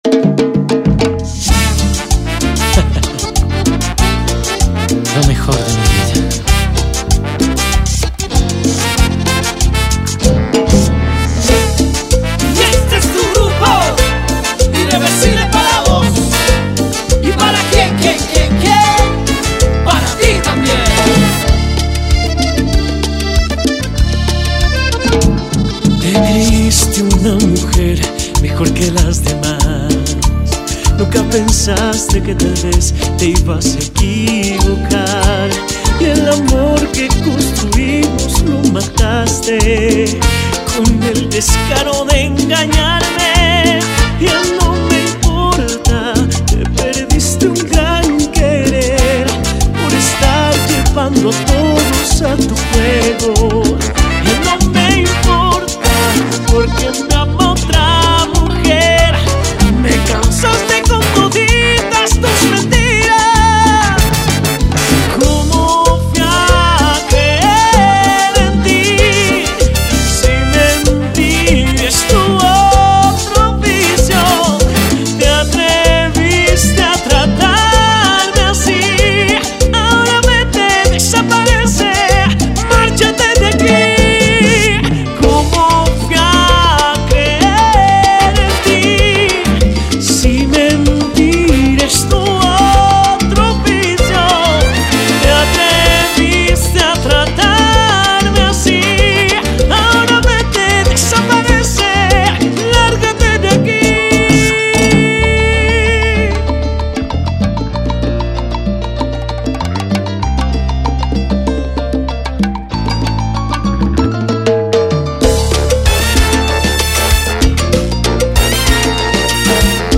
Cumbia Latina